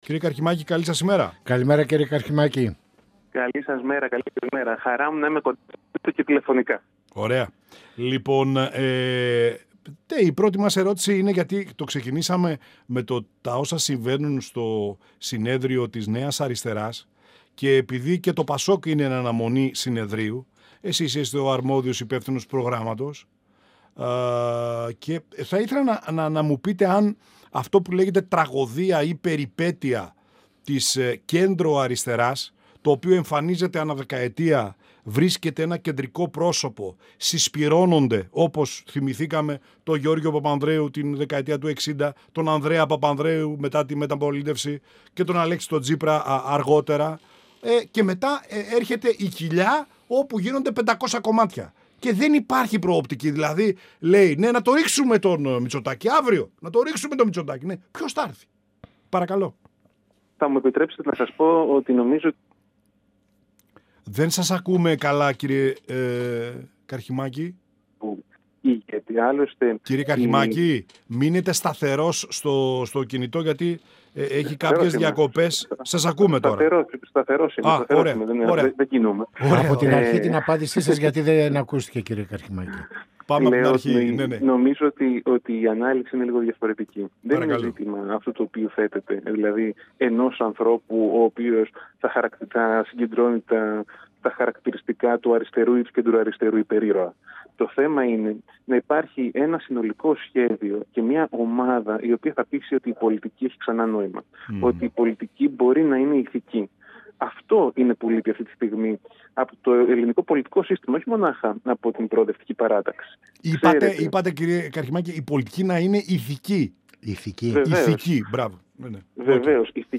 Στις πολιτικές εξελίξεις, στις προγραμματικές προτεραιότητες του ΠΑΣΟΚ, στους μη ενθαρρυντικούς δημοσκοπικούς δείκτες για το κόμμα της αξιωματικής αντιπολίτευσης αναφέρθηκε ο Υπεύθυνος Προγράμματος του ΠΑΣΟΚ Λευτέρης Καρχιμάκης, μιλώντας στην εκπομπή «Πανόραμα Επικαιρότητας»  του 102FM της ΕΡΤ3.